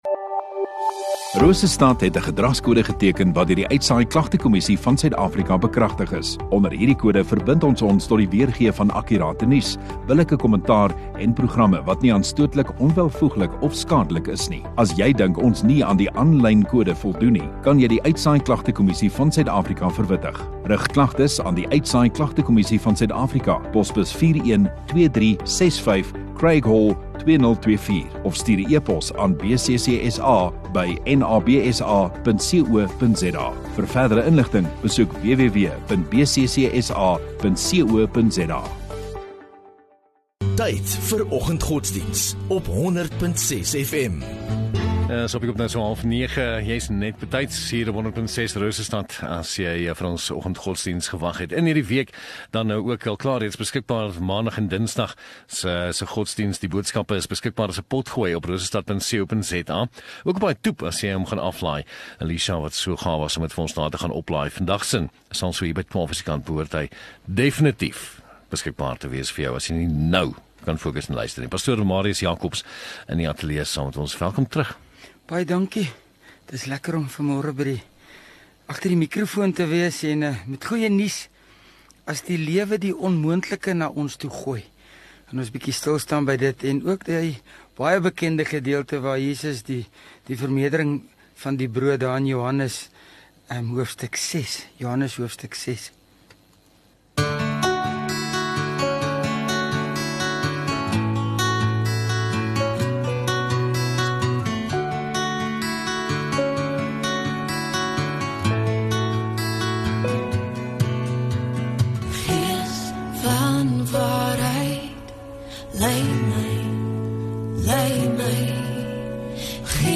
3 Jul Woensdag oggenddiens